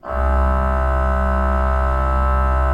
Index of /90_sSampleCDs/Propeller Island - Cathedral Organ/Partition G/HOLZGEDAKT M